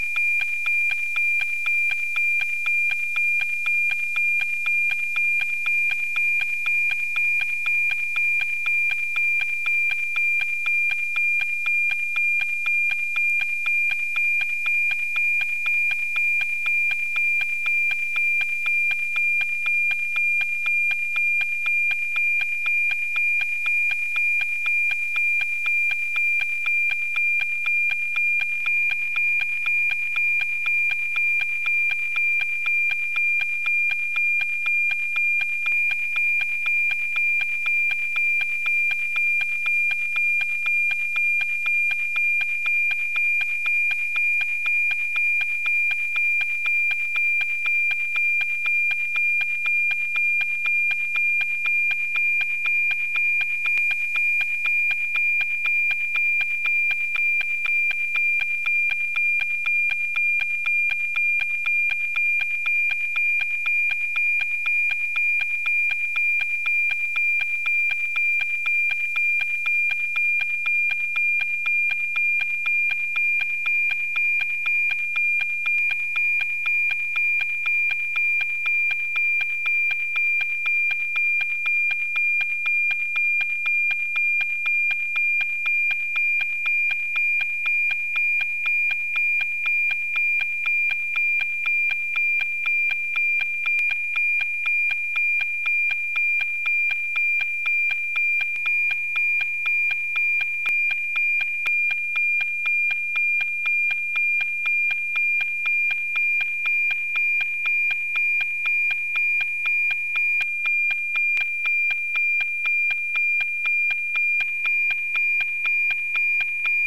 Manually Decoding a NOAA APT Transmission
The hands-on part is relatively basic: plug-in a V-dipole antenna into your SDR dongle, face the antenna North, use your favourite satellite tracker to work out the time a satellite will be over you, tune in to it’s frequency, and save the recording (in my case using SDR++).
The AM signal is somewhere in there, and typically you’d pass the wav to a decoder and end up with an image.
NOAA-19APT.mp3